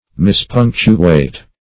Search Result for " mispunctuate" : The Collaborative International Dictionary of English v.0.48: Mispunctuate \Mis*punc"tu*ate\ (?; 135), v. t. To punctuate wrongly or incorrectly.